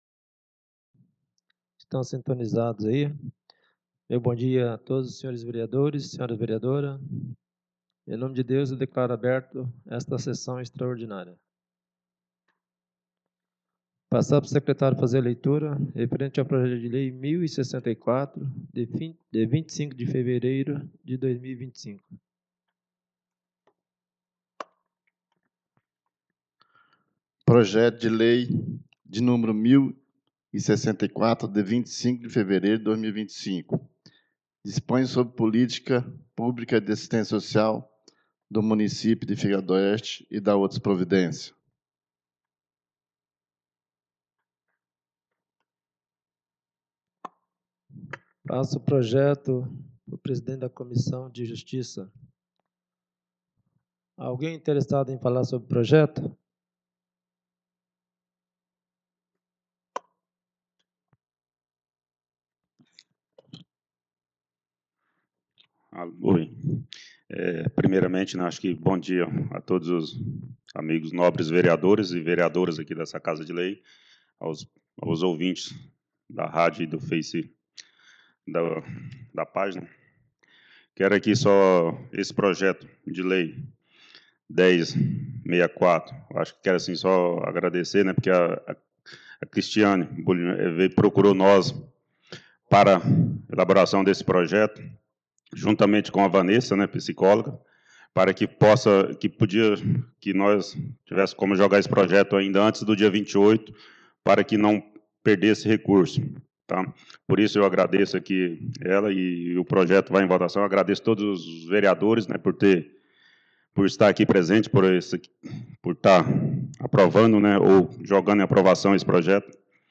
2° SESSÃO EXTRAORDINÁRIA DE 27 DE FEVEREIRO DE 2025